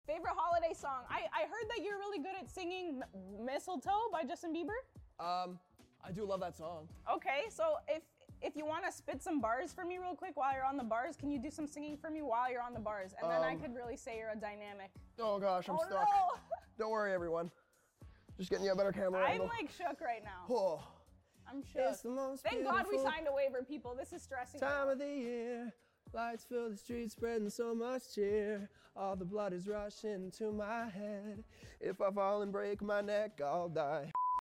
Mp3 Sound Effect